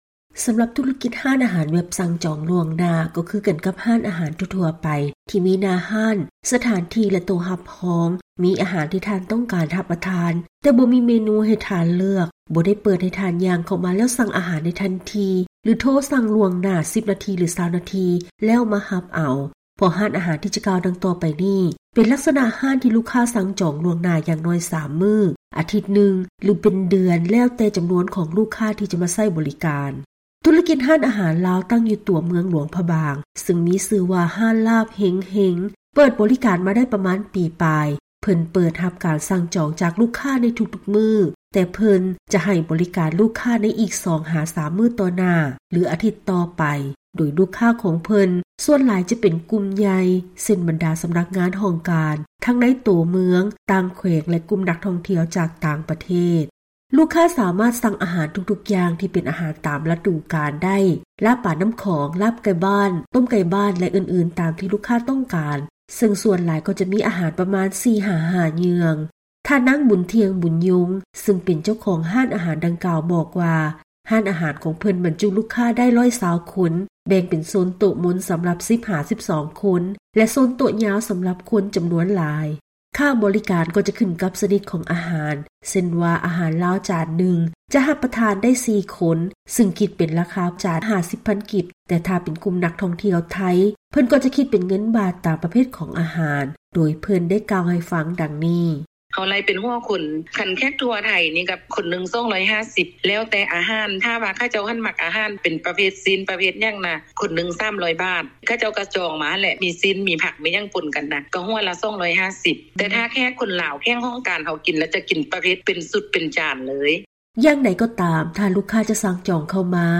ເຊີນຮັບຟັງລາຍງານກ່ຽວກັບ ການເຮັດທຸລະກິດຮ້ານອາຫານ ແບບຈອງລ່ວງໜ້າໄວ້.